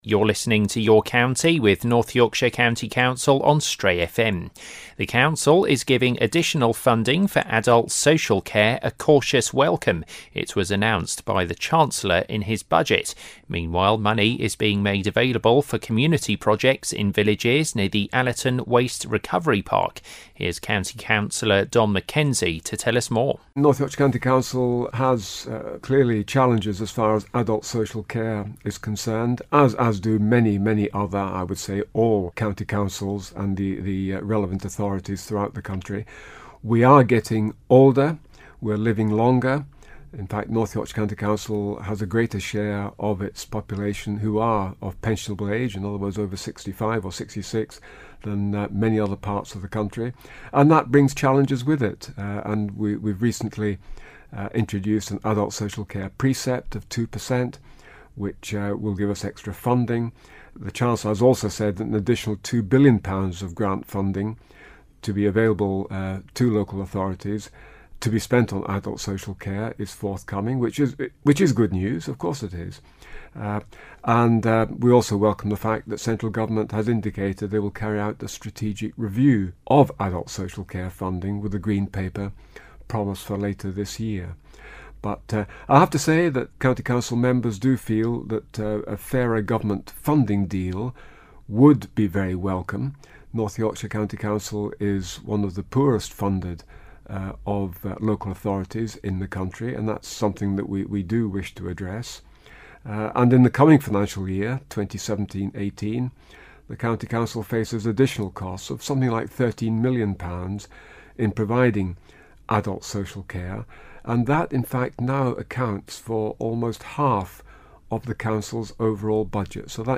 County Councillor Don Mackenzie has been telling Stray FM about extra funding for adult social care and grants for community projects in villages near the Allerton Waste Recovery Park.